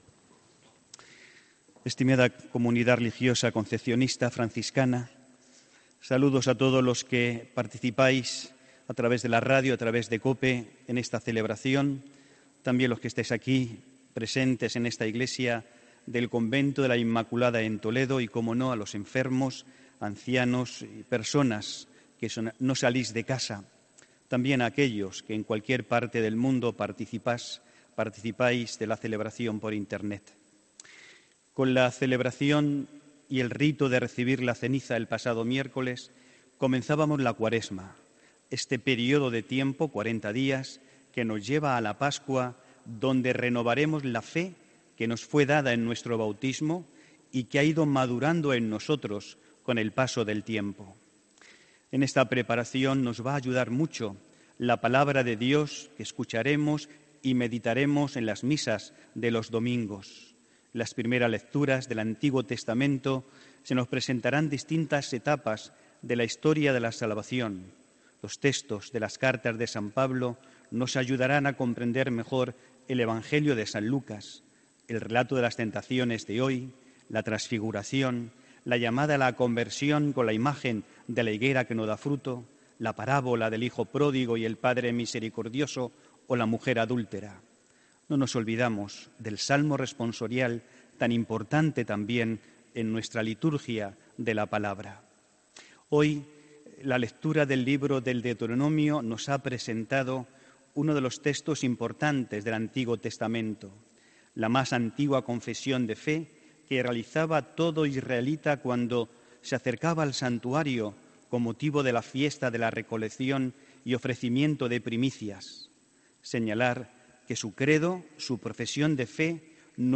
HOMILÍA 10 MARZO 2019